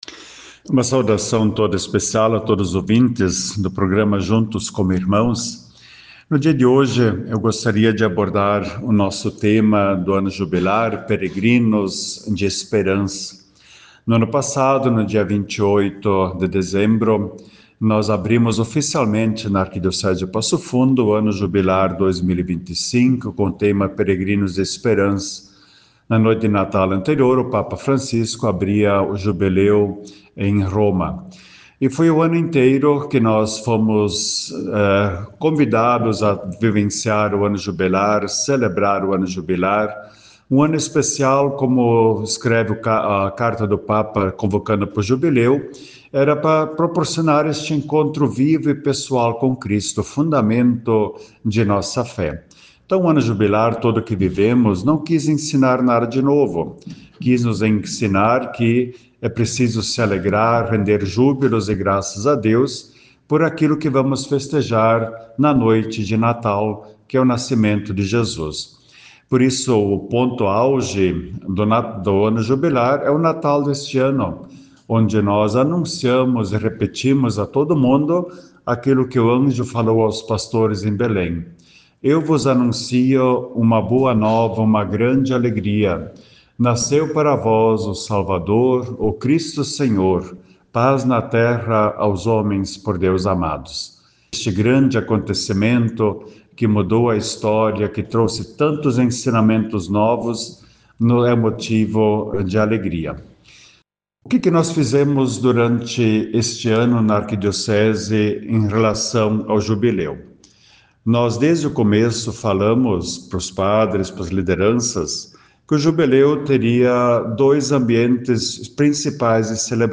O  arcebispo dom Rodolfo Luís Weber realizou um balanço das atividades do Jubileu, no programa Juntos Como Irmãos, da  Rádio Planalto News (92.1)  no sábado, 20 de dezembro de 2025.